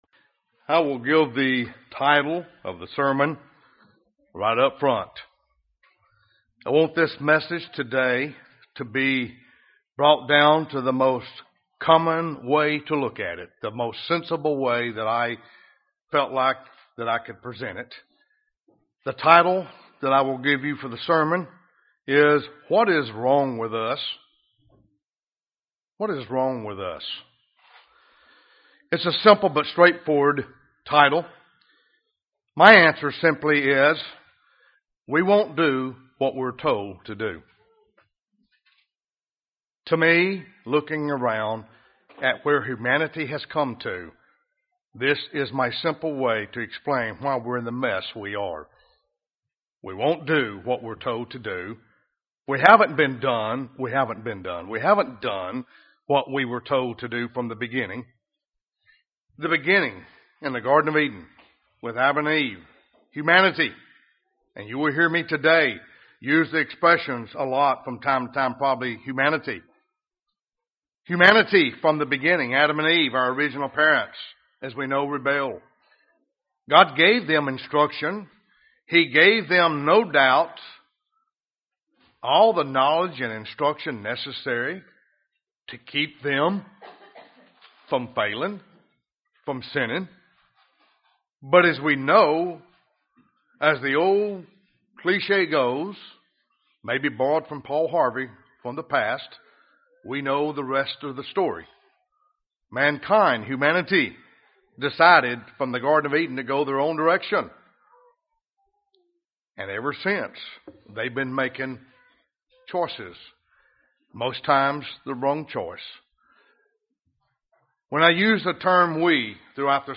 Sermons
Given in Birmingham, AL Huntsville, AL